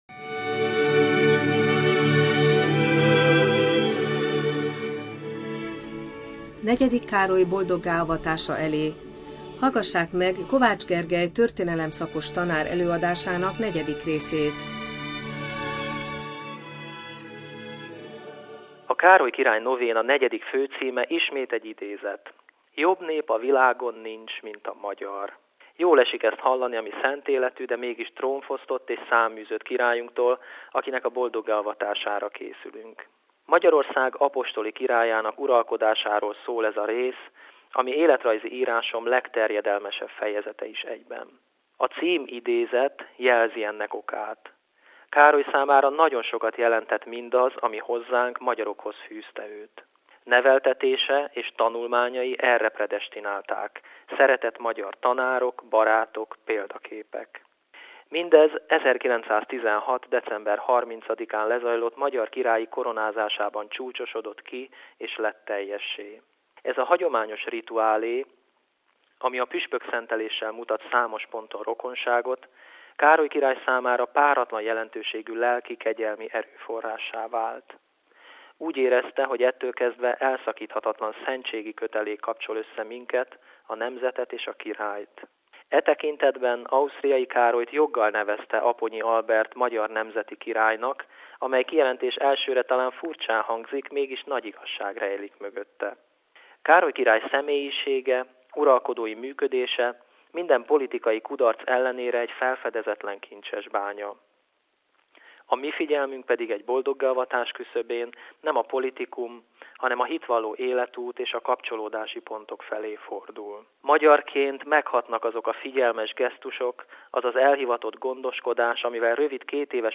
történelem szakos tanár előadása